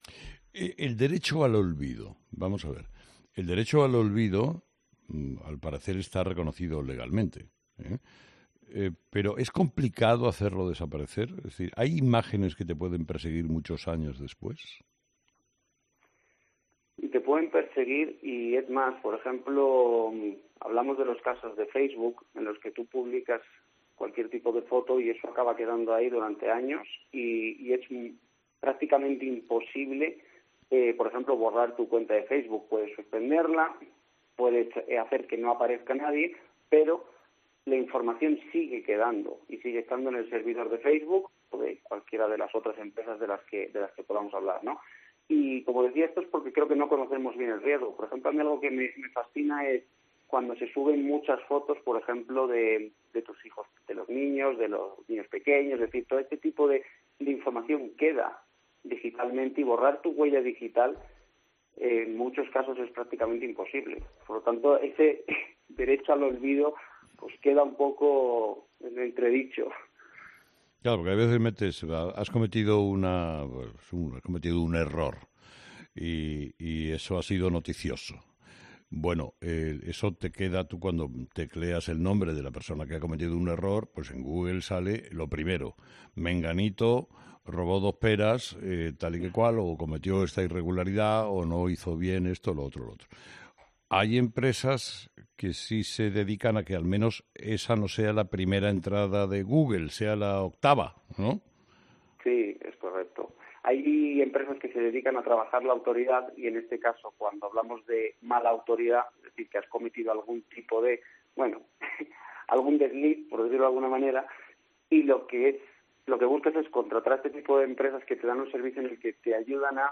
experto en protección de datos